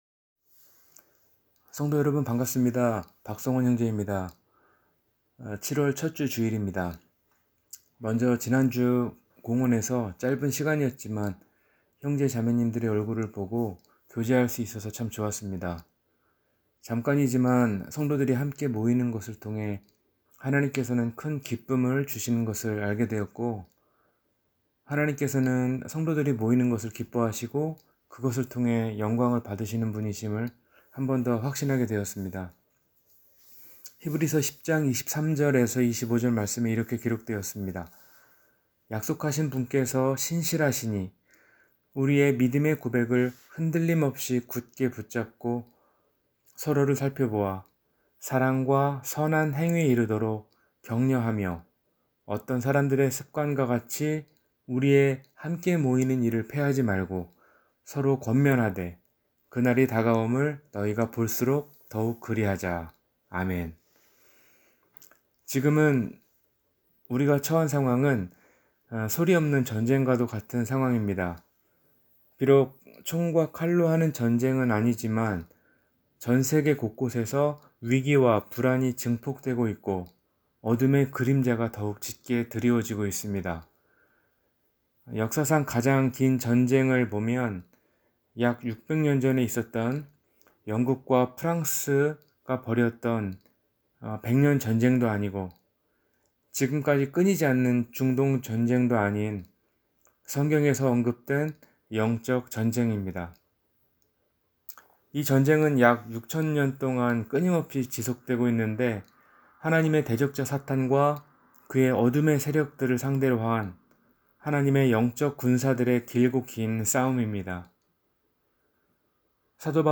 마지막 두 제자 – 주일설교